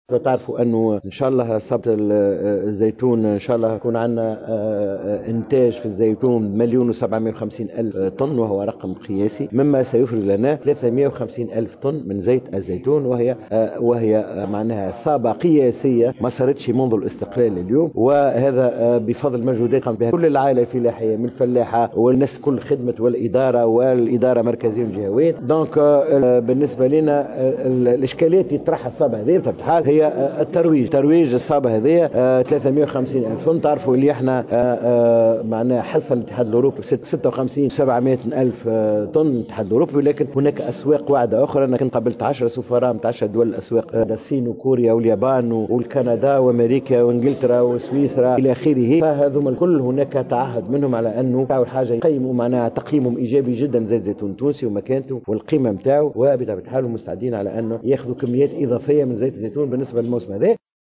عقد وزير الفلاحة و الموارد المائية و الصيد البحري سمير الطيب صباح اليوم الخميس 28 نوفمبر 2019، ندوة صحفية قدم خلالها مؤشرات إنتاج زيت الزيتون لهذا العام، مشيرا إلى أنها صابة قياسة لم تُسجلّ منذ الاستقلال حسب تعبيره.